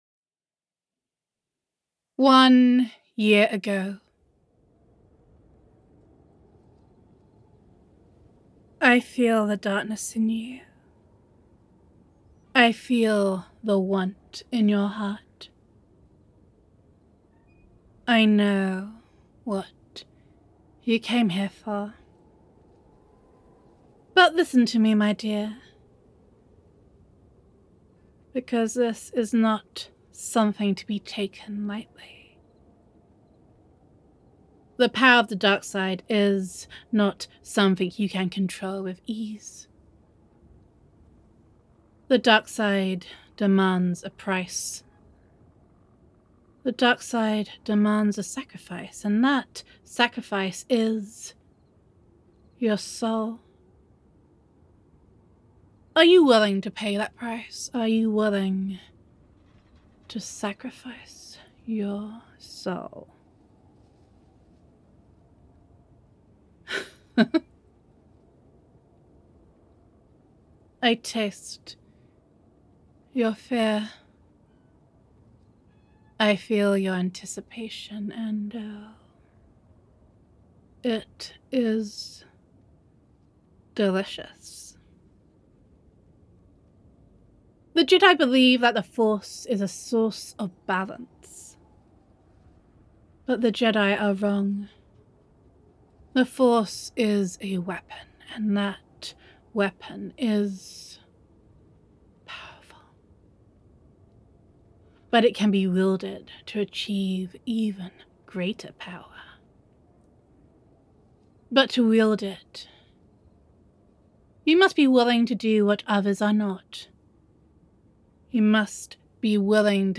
"LADY SITH ROLEPLAY